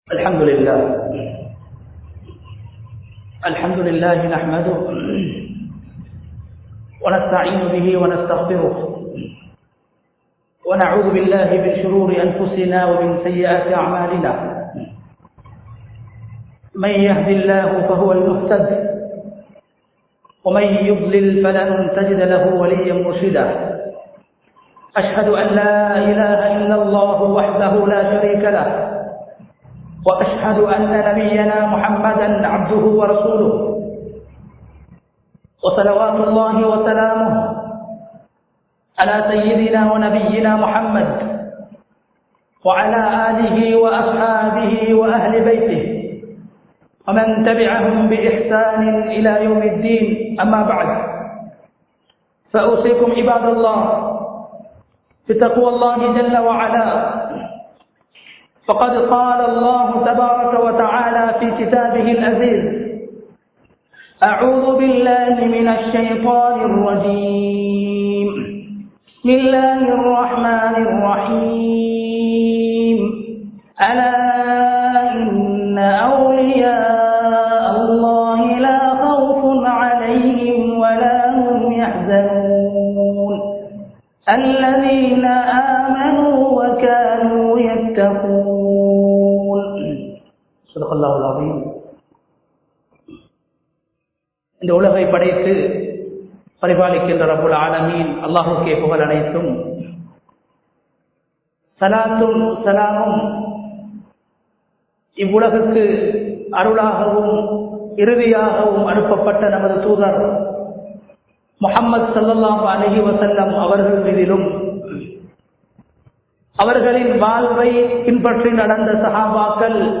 நபி(ஸல்)அவர்கள் கேட்ட 03 விடயங்கள் | Audio Bayans | All Ceylon Muslim Youth Community | Addalaichenai
Umar Jumuah Masjith (Brandia Watte)